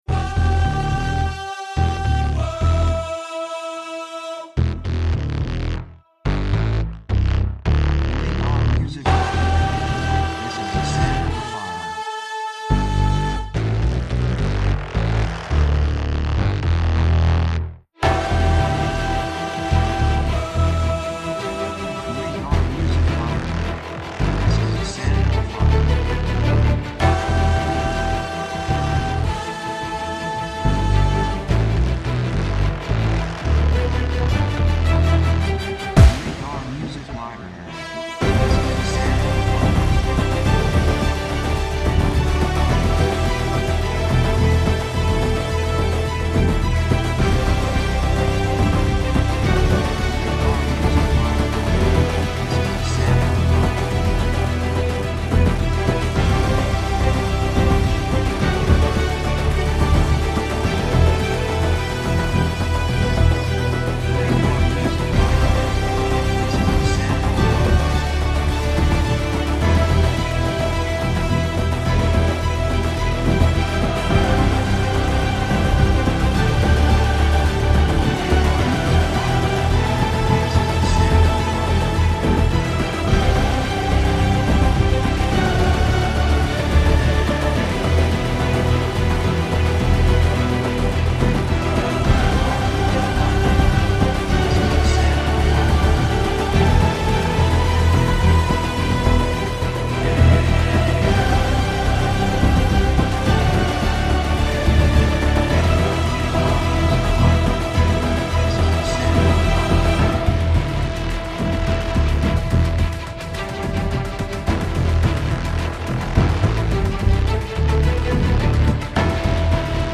雰囲気激しい, 壮大, 決意, 喜び
曲調ニュートラル
楽器オーケストラ, パーカッション, ボーカル, 手拍子
サブジャンルシネマティック, オーケストラハイブリッド
テンポやや速い